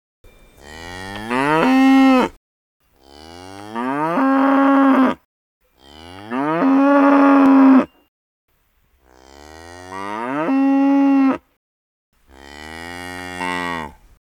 BigDogBarking_02